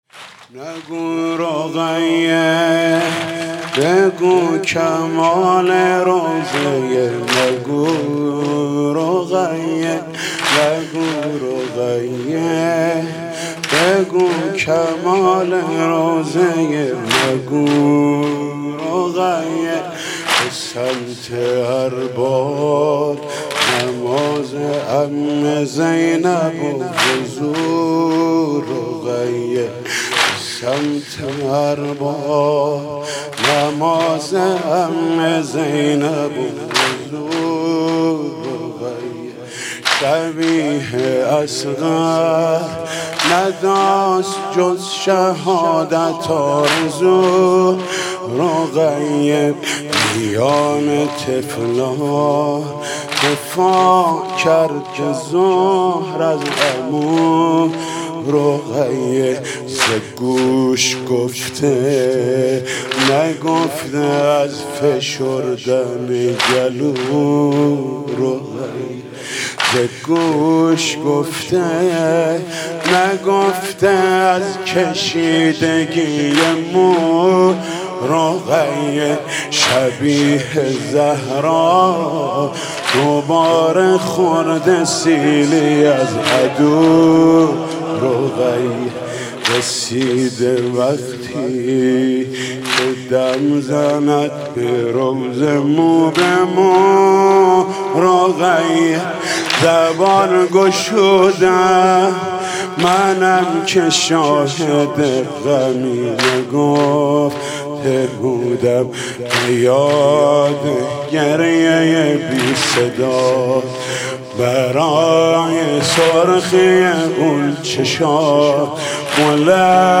نگو رقیه بگو کمال روضه